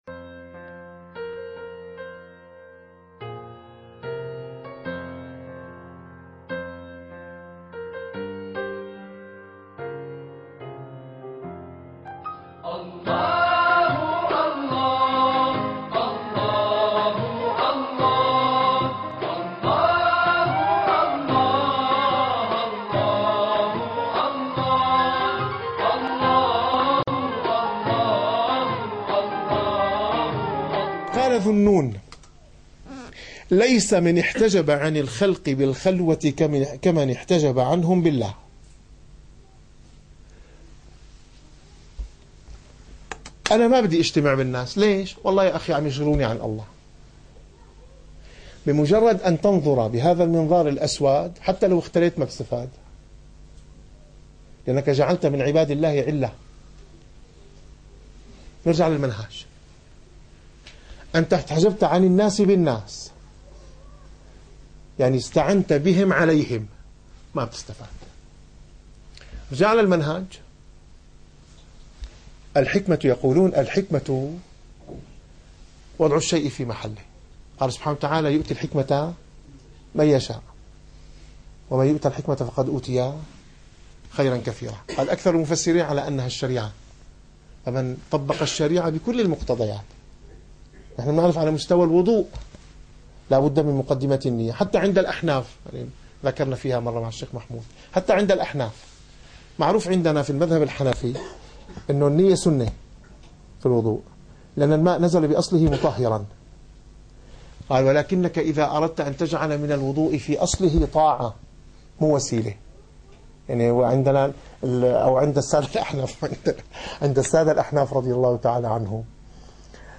- الدروس العلمية - الرسالة القشيرية - الرسالة القشيرية / الدرس التاسع والثلاثون.